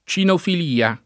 cinofilia [ © inofil & a ] s. f.